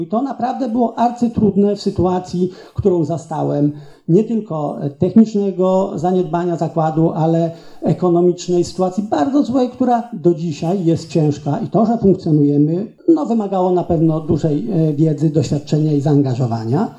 Stargardzki szpital gorącym tematem XXIII sesji Rady Powiatu